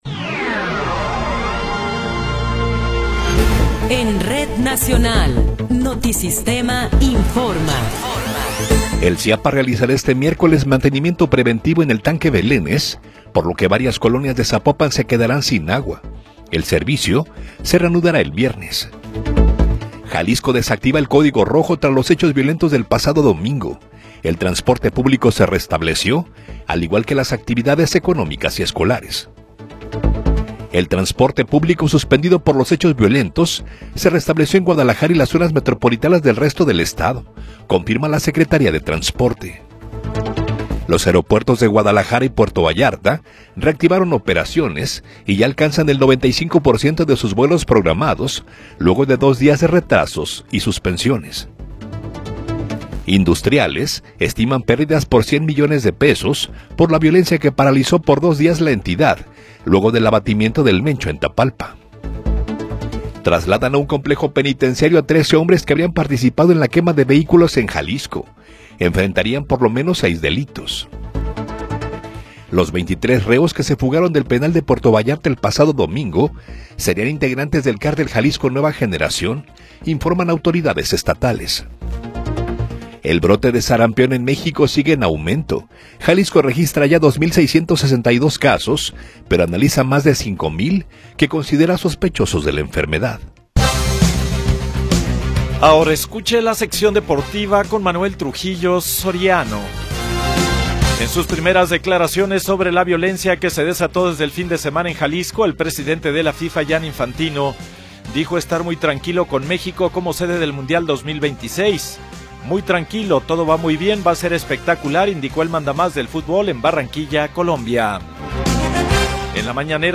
Noticiero 21 hrs. – 24 de Febrero de 2026